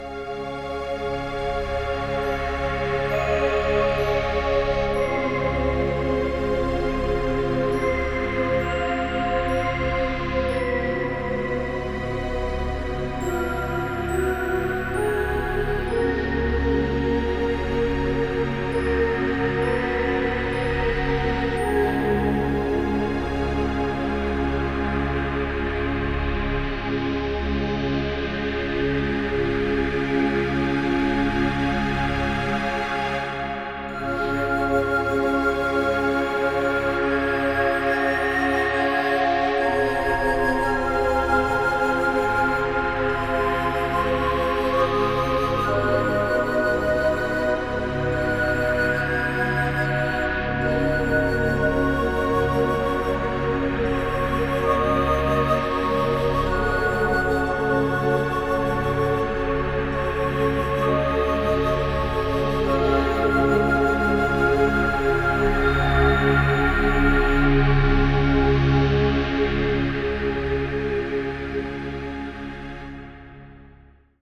varied, some rhythm, nice melodies and nature-sounds